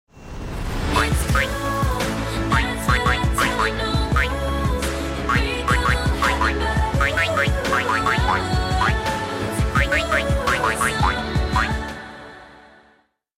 Song 3: Emotionaler Synth-Pop mit bittersüßer Note!